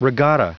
Prononciation du mot regatta en anglais (fichier audio)
Prononciation du mot : regatta